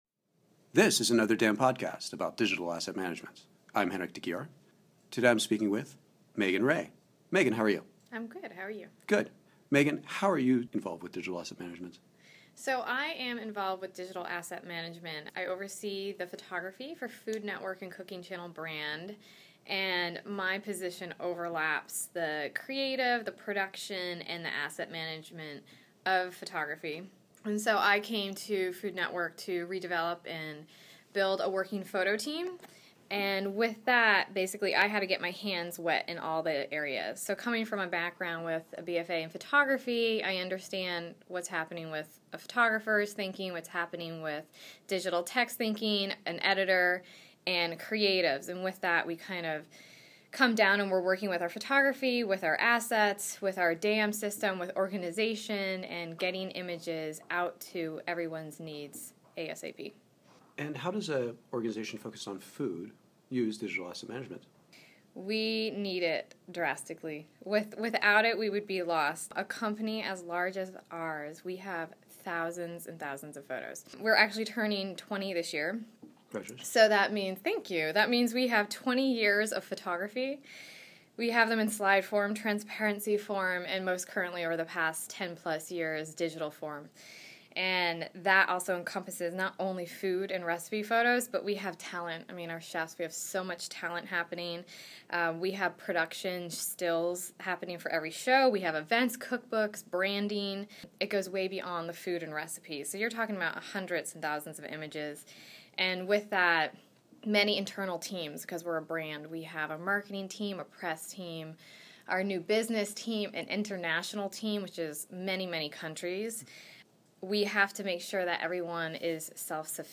Another DAM Podcast interview